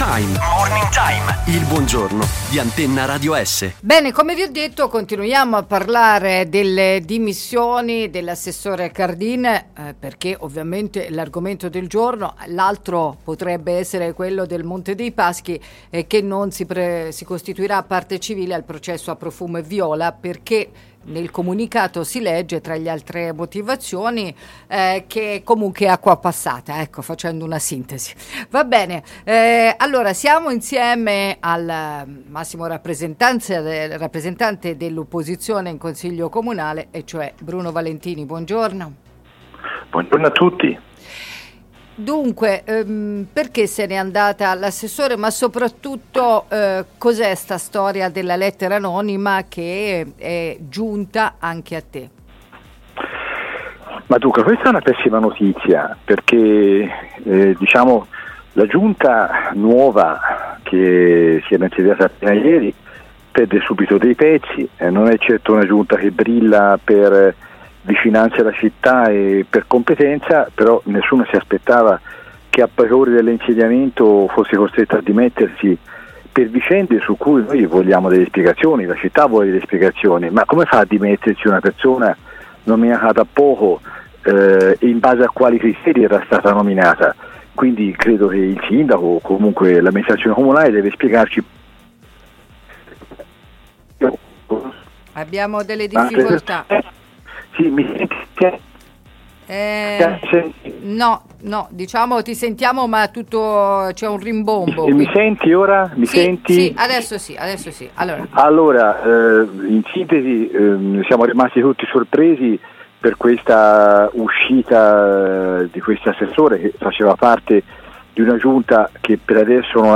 Chiede invece spiegazioni Bruno Valentini che nell’intervista ad Antenna Radio Esse ha analizzato la situazione politica della maggioranza e raccontato di aver consegnato la lettera anonima ricevuta alla Procura perchè indaghi sulle accuse che erano mosse nei confronti dell’assessore Cardin.